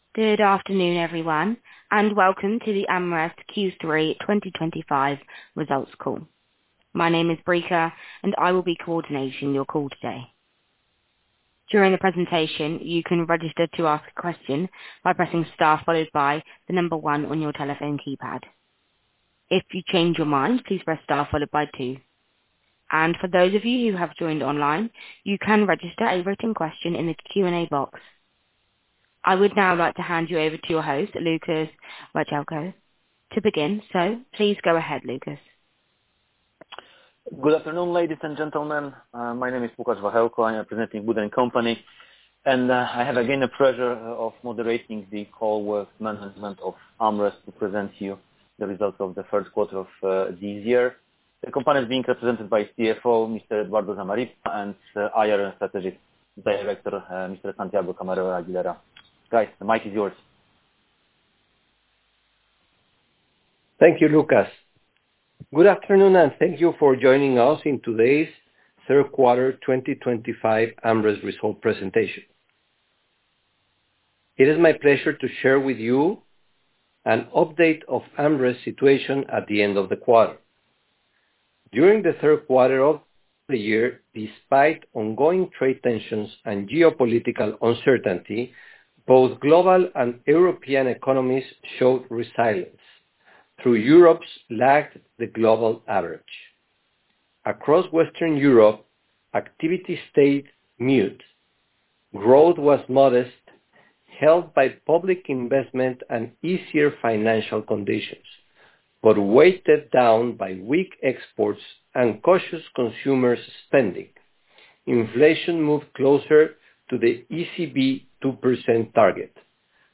Grabación - Teleconferencia con inversores que resume los resultados financieros del T3 2025